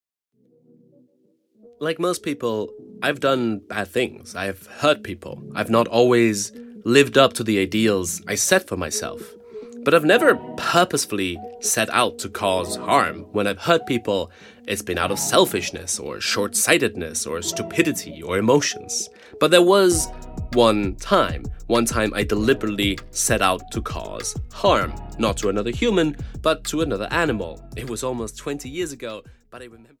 Drowning a Dragonfly (EN) audiokniha
Ukázka z knihy